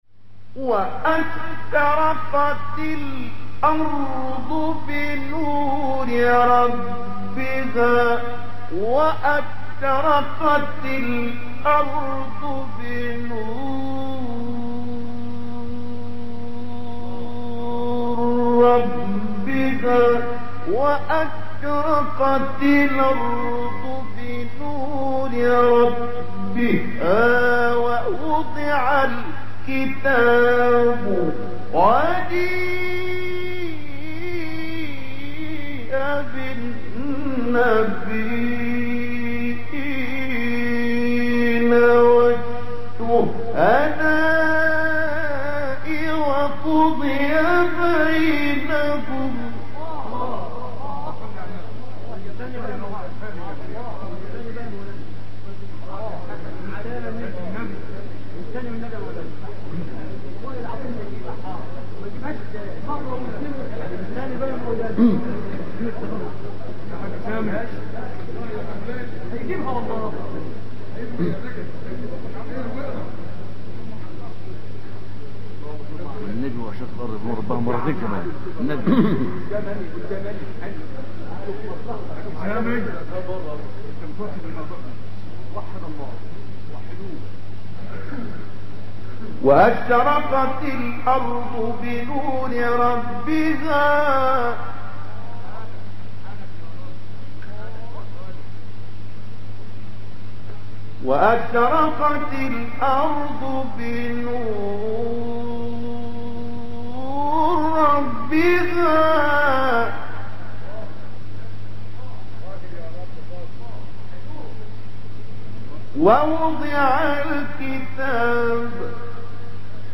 مقام : رست